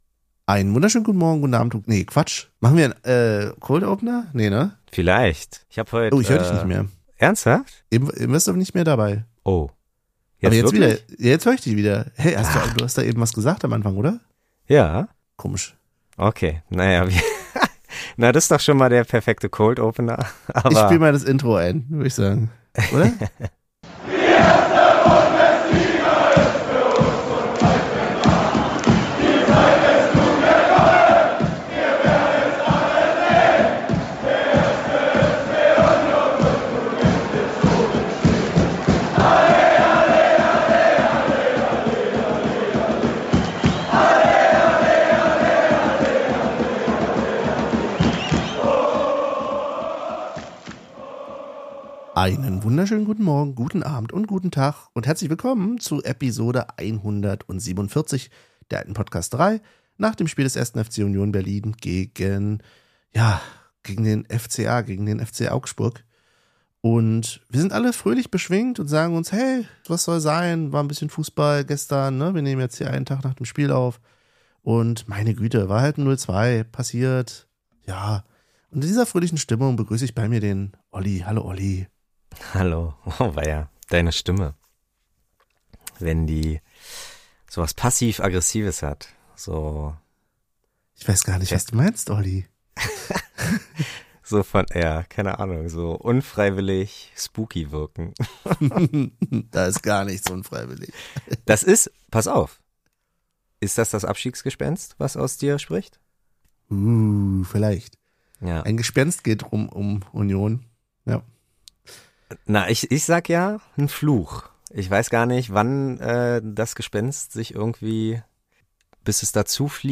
1 Special: Interview